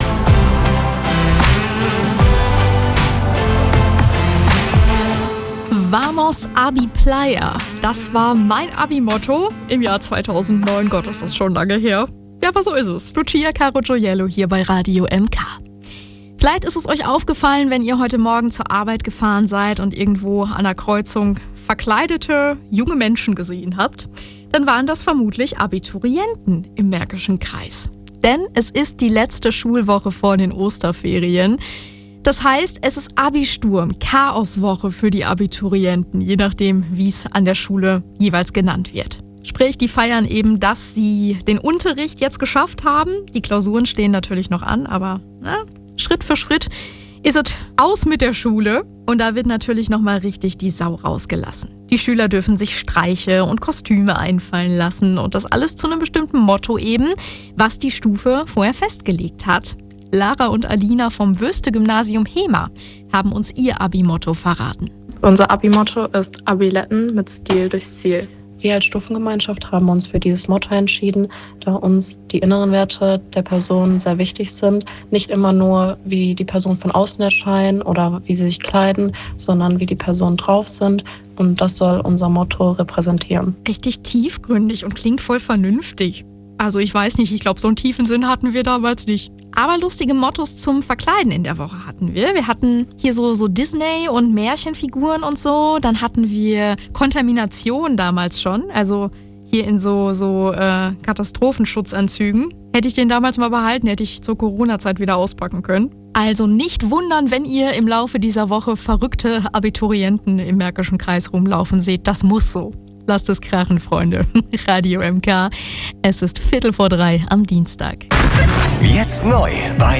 Kurzbeitrag im Radio MK Bilder: "Preview" der Abizeitung 2025